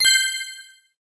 UI_Click_Yes.ogg